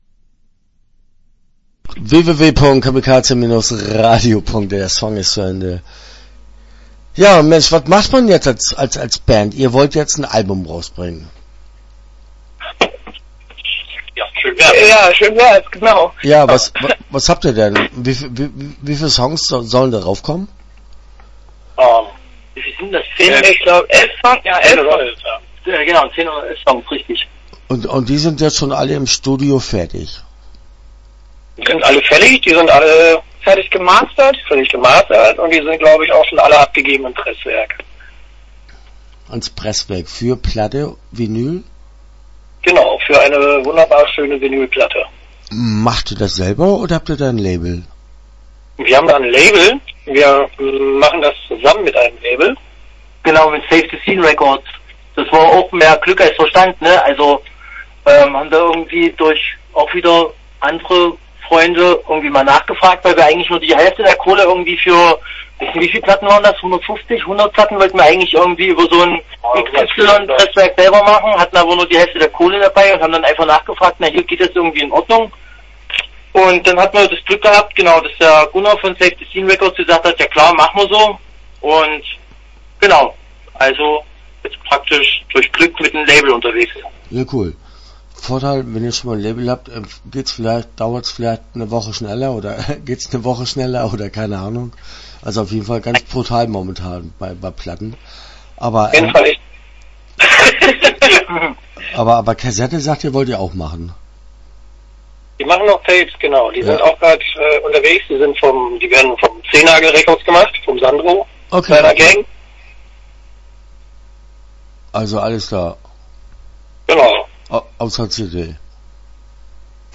Start » Interviews » Todschick Gekleidet